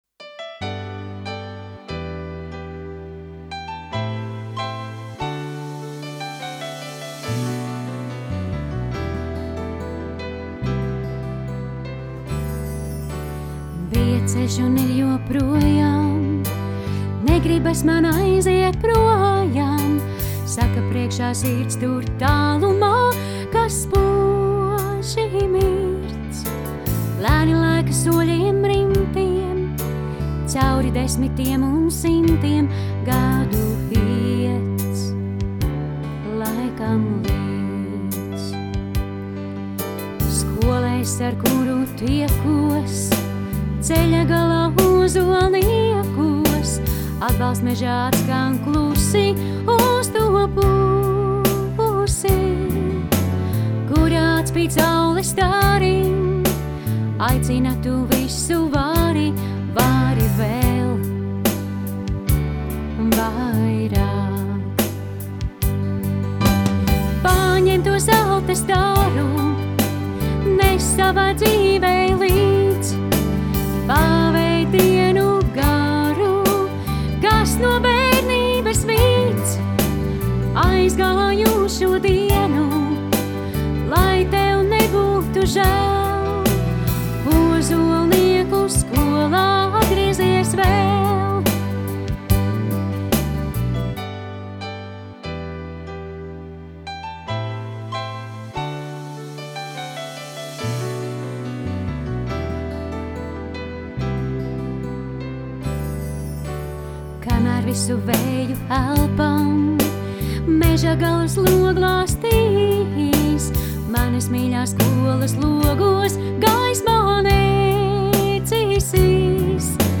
Skolas_himna_ar_balsi_OZVSK.mp3